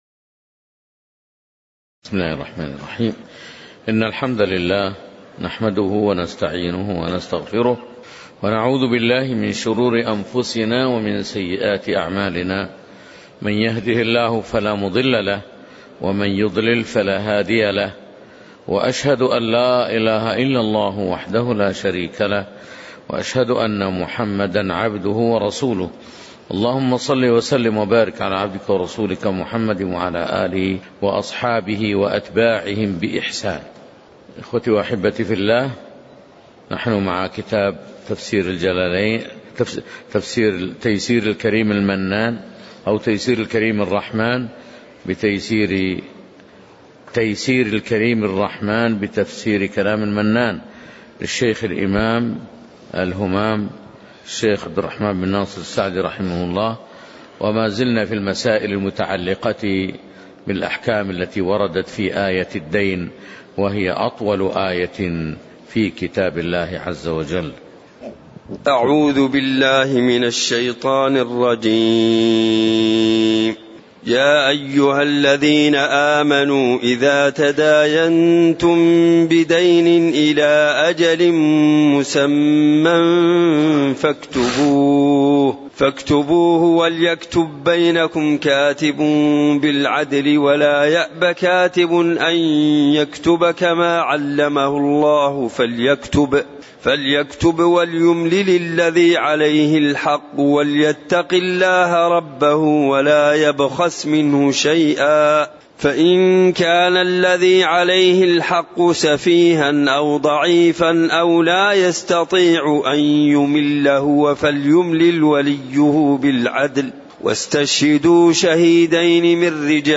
تاريخ النشر ٢٦ شعبان ١٤٣٩ هـ المكان: المسجد النبوي الشيخ